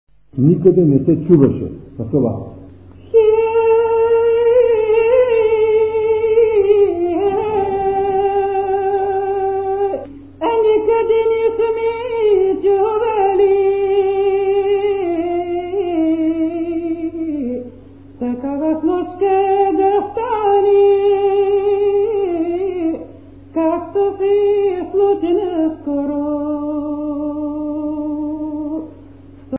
музикална класификация Песен
форма Петредична
размер Безмензурна
фактура Едногласна
начин на изпълнение Солово изпълнение на песен
фолклорна област Североизточна България
начин на записване Магнетофонна лента